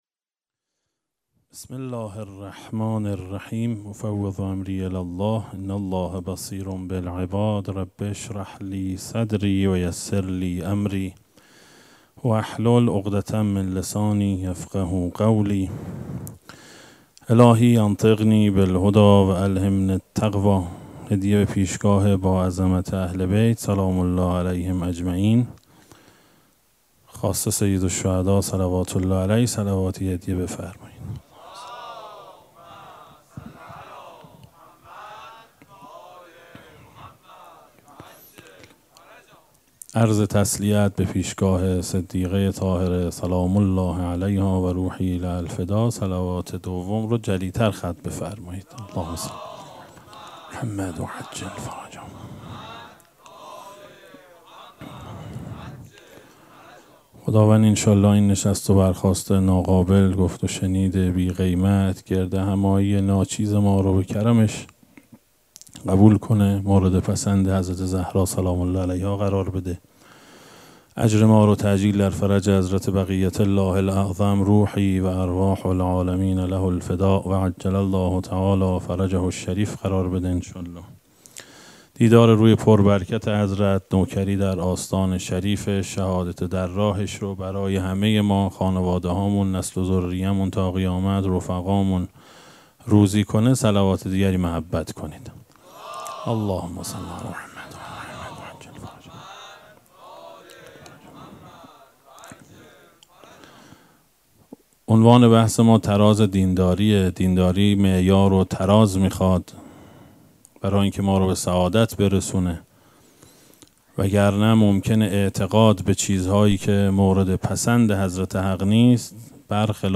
سخنرانی
سخنرانی شب هشتم محرم